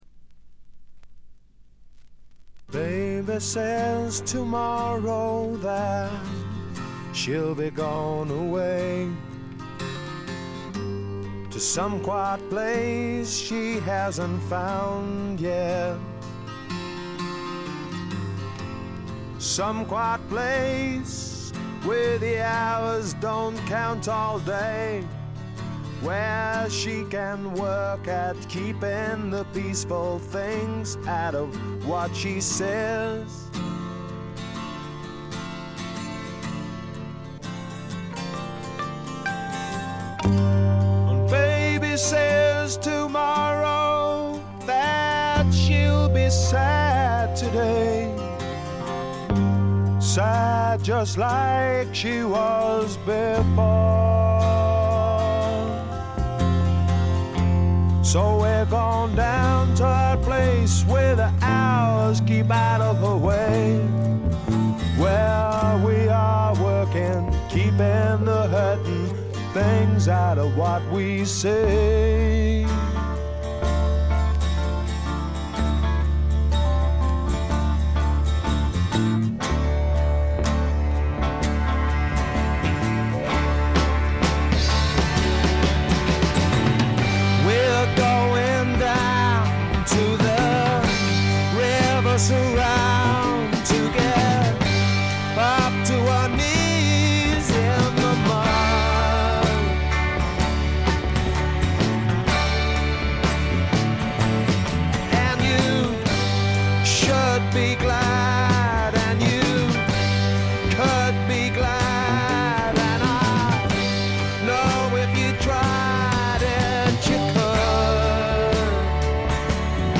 ほとんどノイズ感無し。
というわけでスモーキーなヴォーカルが冴える霧の英国シンガー・ソングライターの金字塔作品です。
試聴曲は現品からの取り込み音源です。